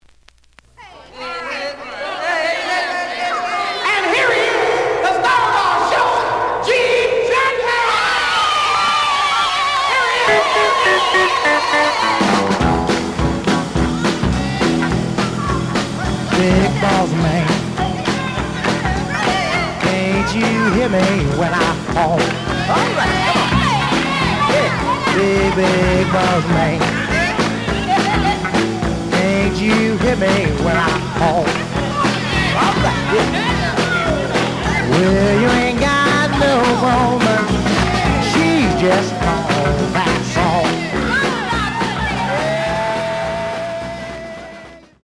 The audio sample is recorded from the actual item.
●Genre: Soul, 60's Soul
Slight noise on A side.